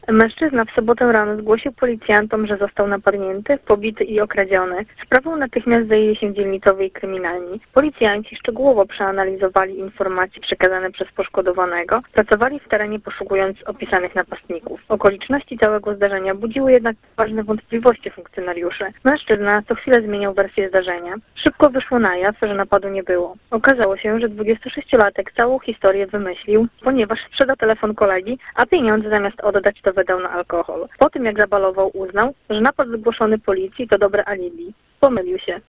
O szczegółach mówi